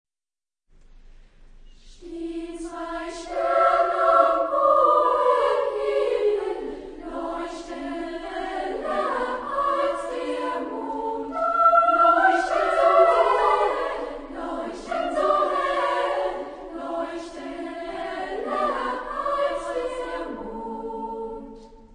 Arr.: Pepping, Ernst (1901-1981) [ Germany ]
Type of Choir: SSA  (3 women voices )
Discographic ref. : 4.Deutscher Chorwettbewerb, 1994